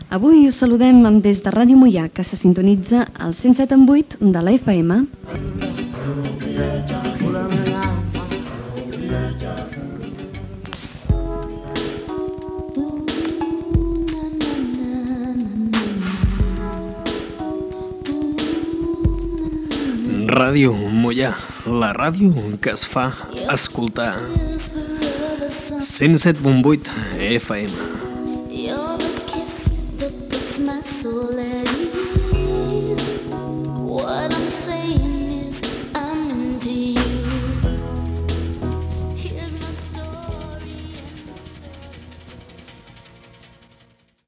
Salutació i indicatiu de l'emissora